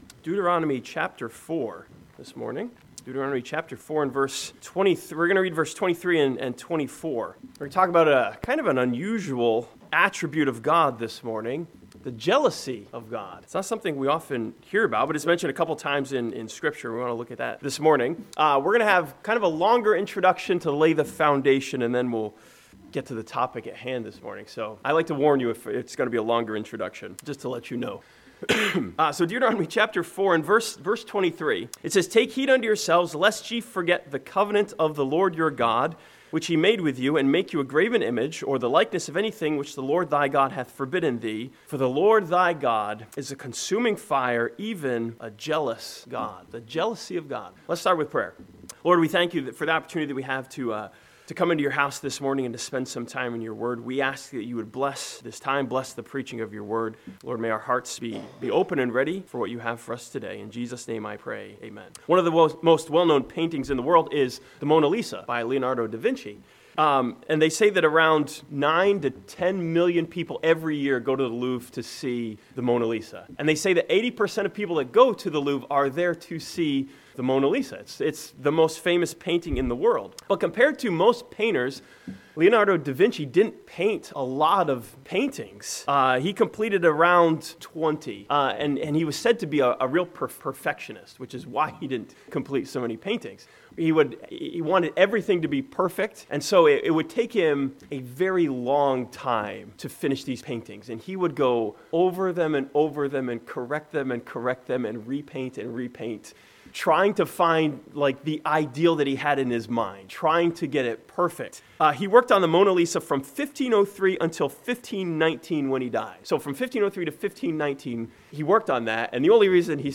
Series: Sunday AM